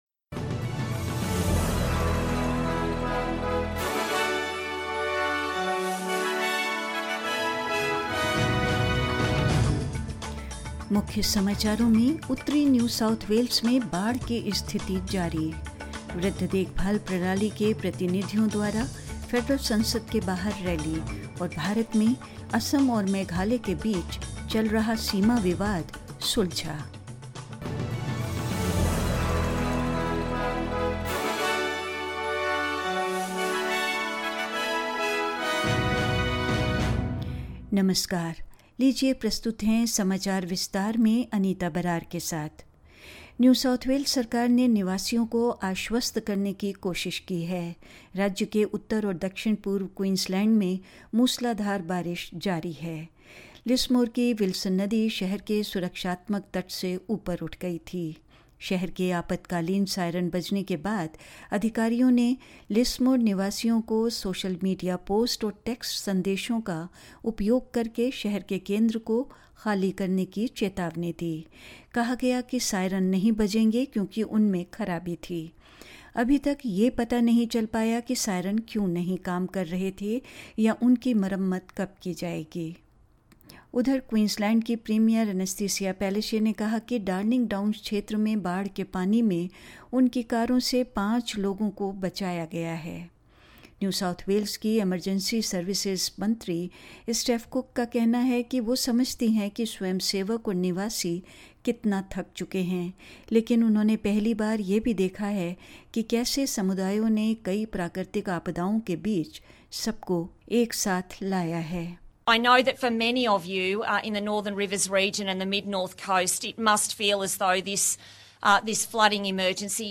SBS Hindi News 30 March 2022: Torrential rains continue to pound the north of the New South Wales